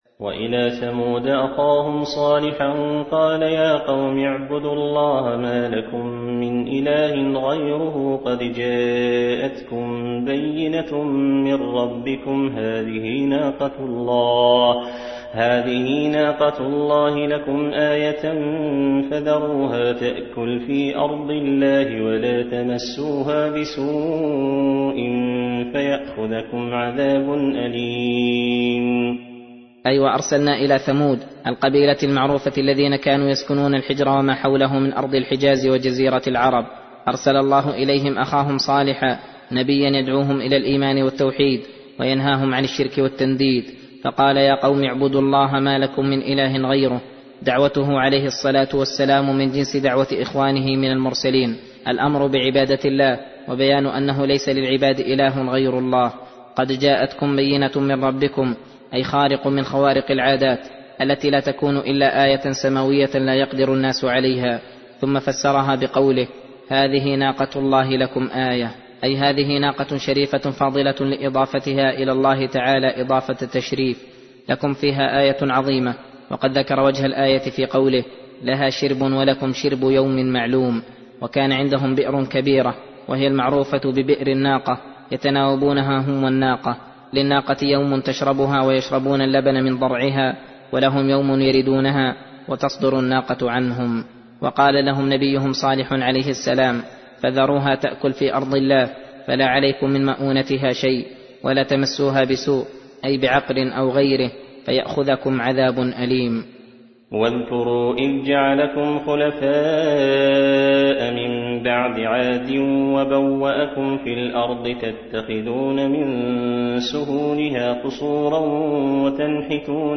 درس (5) : تفسير سورة الأعراف : (73-93)